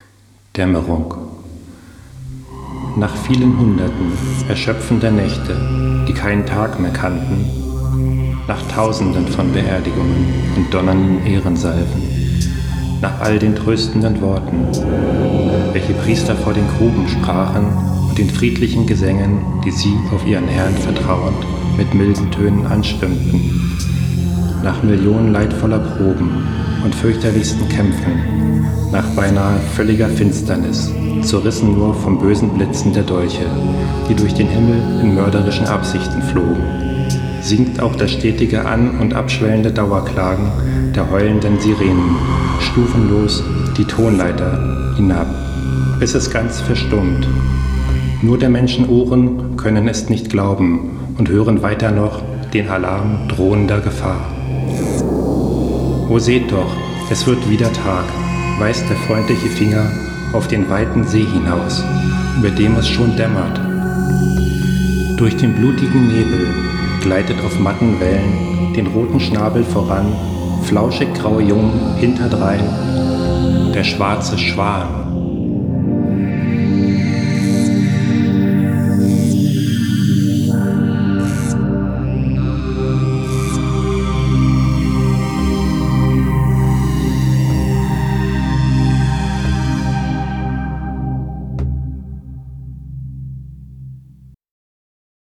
Hier gibt es einige improvisierte Lesungen meiner Texte zum Herunterladen im MP3-Format, in seltenen Fällen sind sie musikalisch untermalt. Es handelt sich um Lo-Fi Produktionen, wenn man sie überhaupt Produktionen nennen mag.